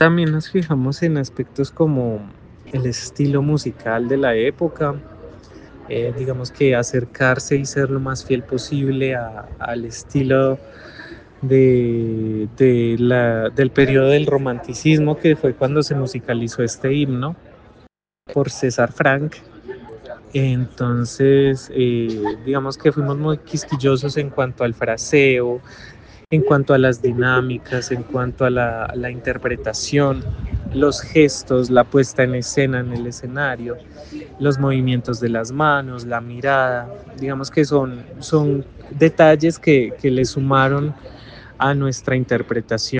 intérprete de canto
pianista
canto libre adultos – canción libre litúrgica